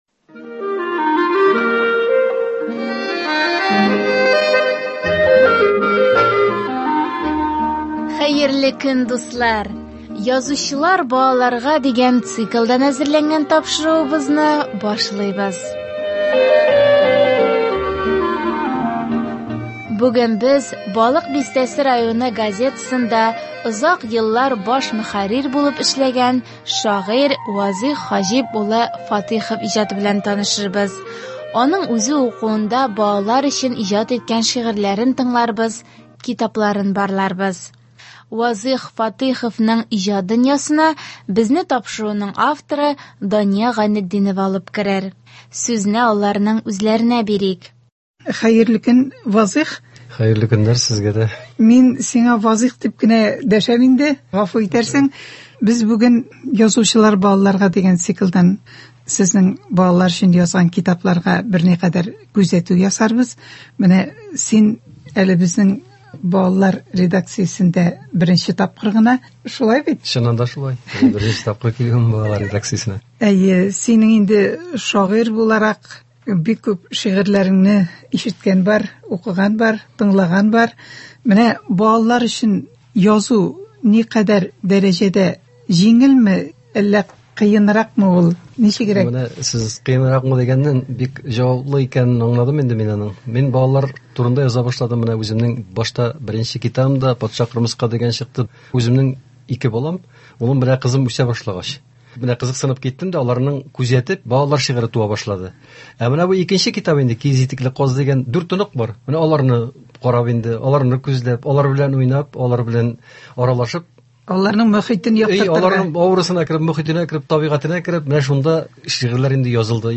аның үзе укуында балалар өчен иҗат иткән шигырьләрен тыңларбыз